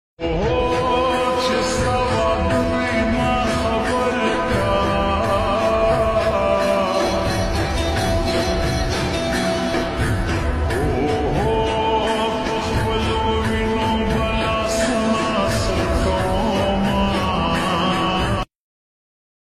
Pashto Song